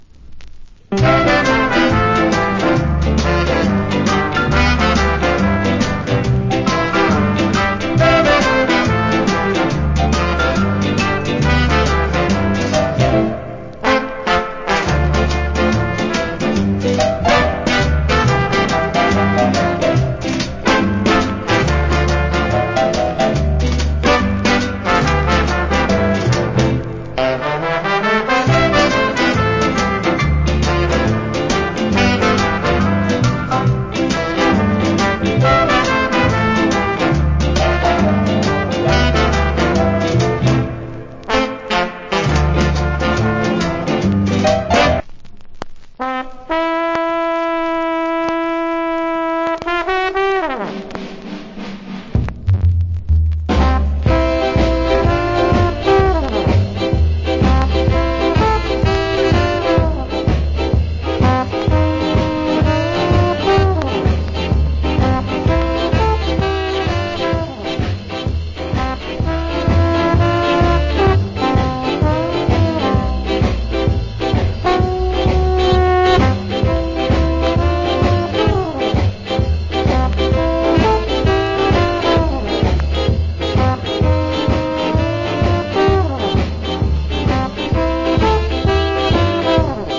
Ska Inst.